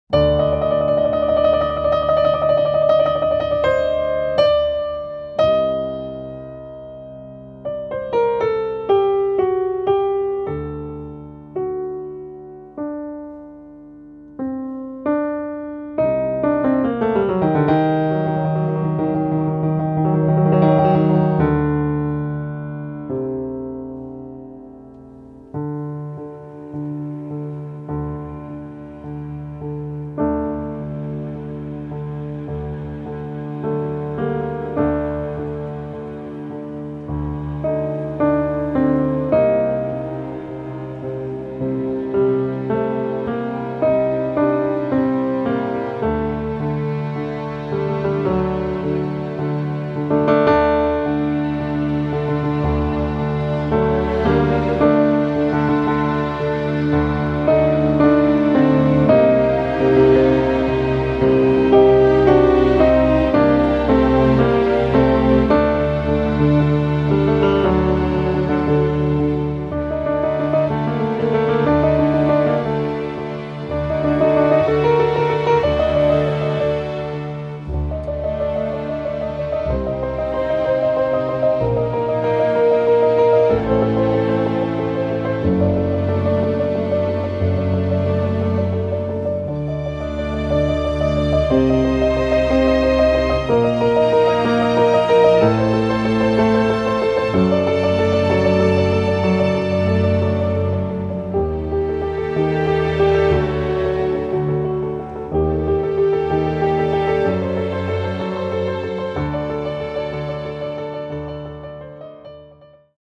un CD per orchestra, pianoforte e strumenti etnici
pianista